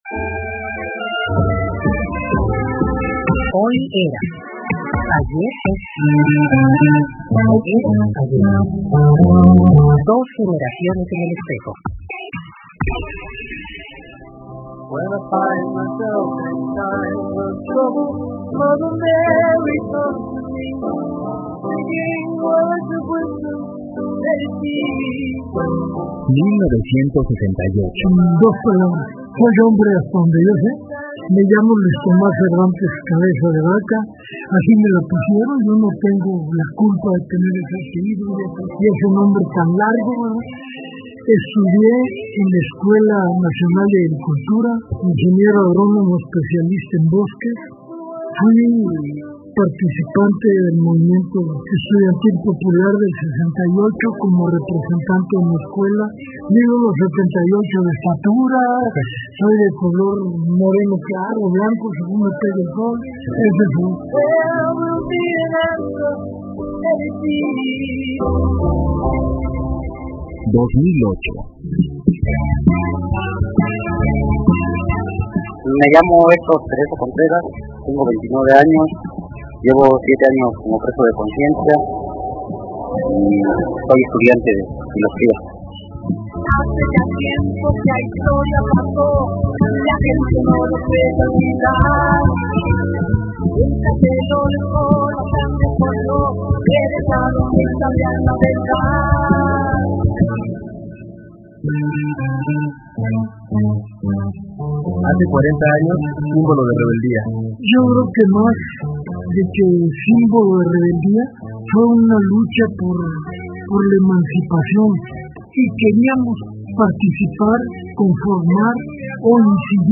Audios de muy baja calidad de entrevistas a
SI QUE SON DE MUY BAJA CALIDAD, PERO AUN ASI SE PUEDE ENTENDER UN 70 POR CIENTO LO QUE DICEN, NO HABRA POSIBILIDAD DE QUE MEJOREN LA CALIDAD DEL AUDIO.